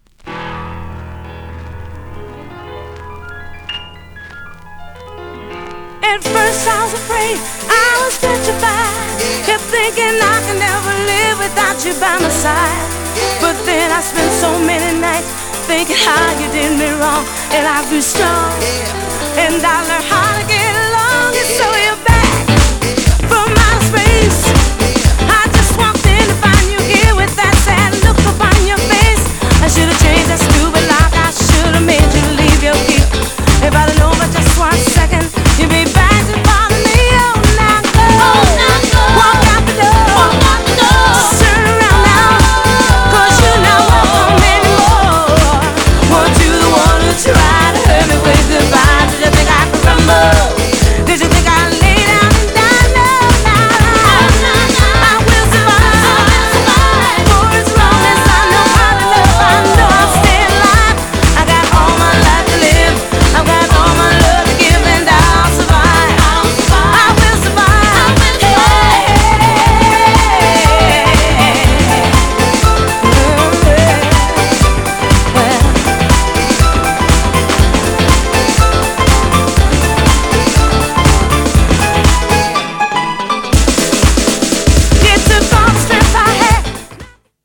※元々のプレスが悪いため多少のサーフィスノイズ出ます。
GENRE House
BPM 121〜125BPM